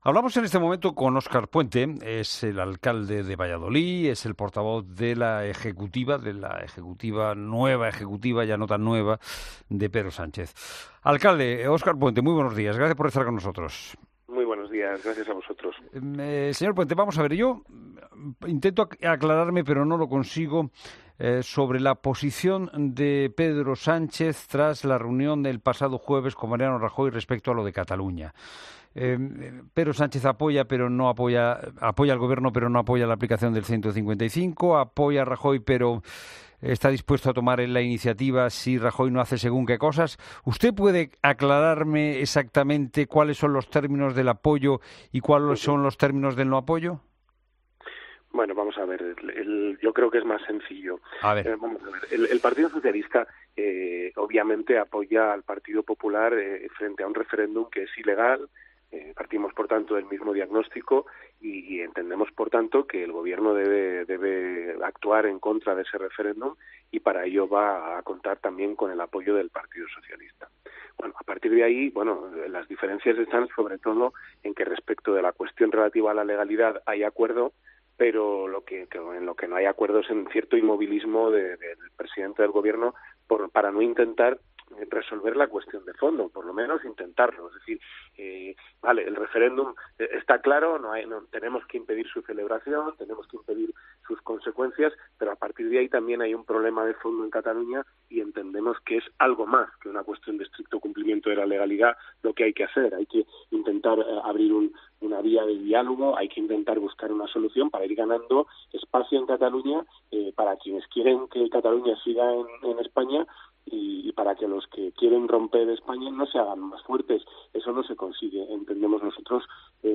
Óscar Puente, portavoz de la Ejecutiva del PSOE, en "La Mañana Fin de Semana"